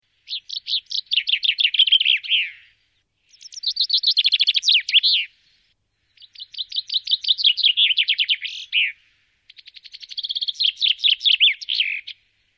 Buchfink
So klingt der Buchfink
der-buchfink-stimme.mp3